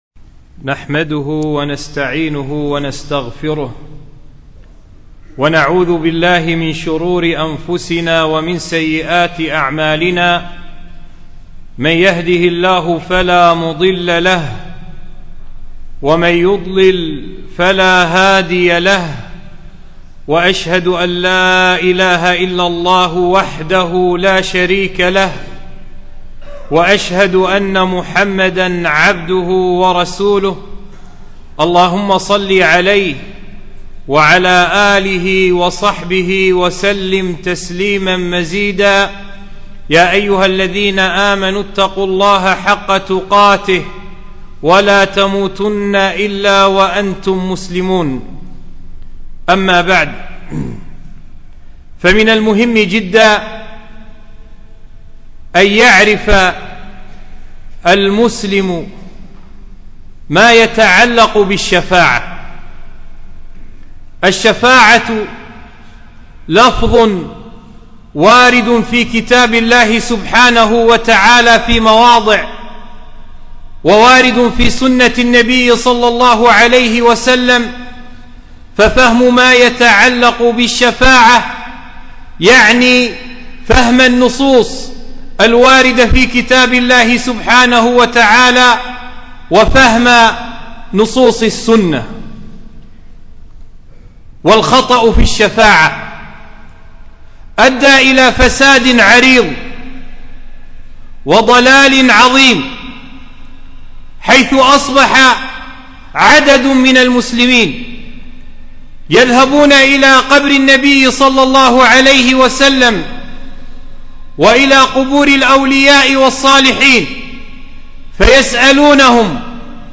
خطبة - الشفاعة